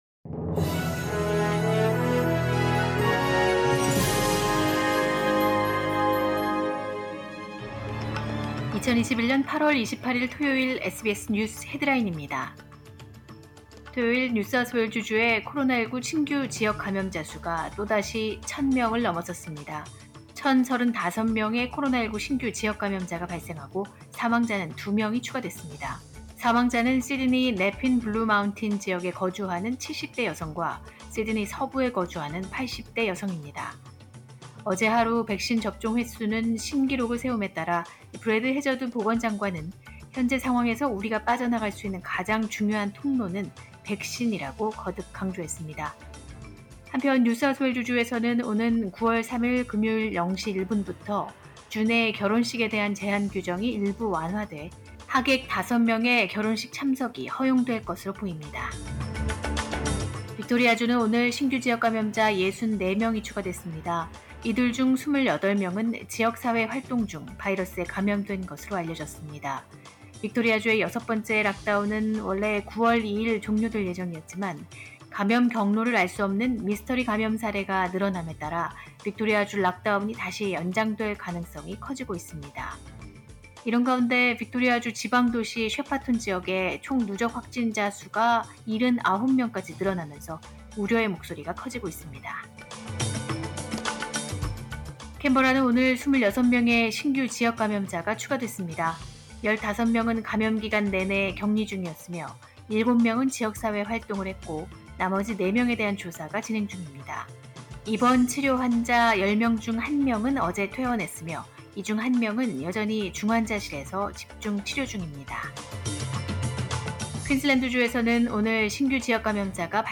2021년 8월 28일 토요일 SBS 뉴스 헤드라인입니다.